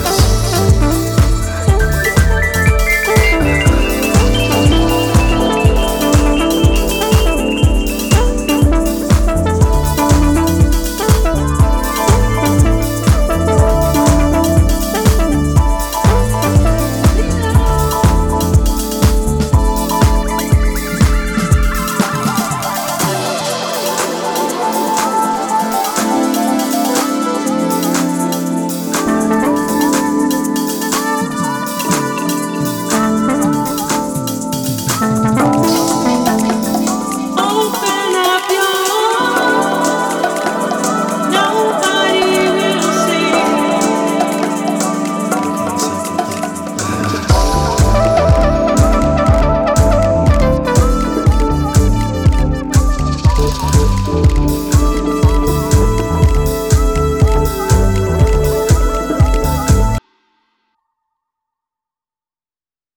秀逸なディープ・ハウスをじっくり堪能できるおすすめ盤です！